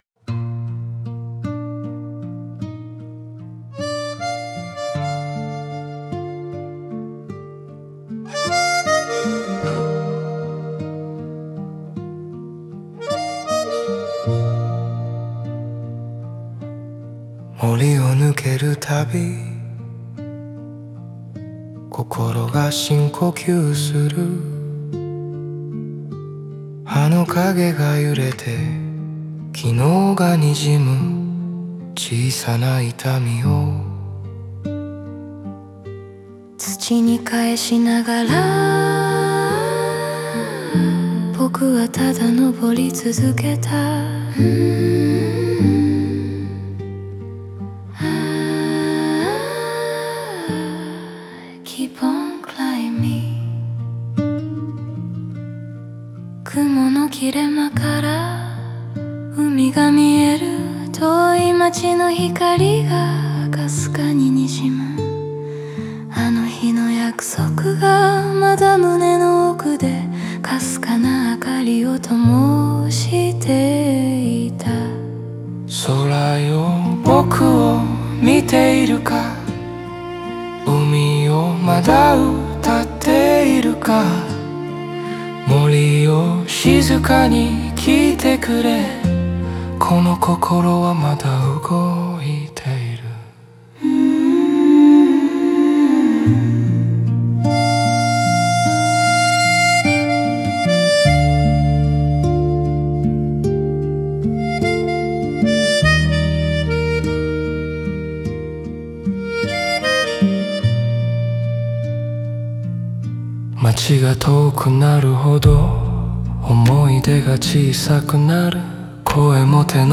オリジナル曲♪
森や海、空といった自然は語りかけるように主人公の内面を映し、静かな声のコーラスは風や記憶の残響のように寄り添う。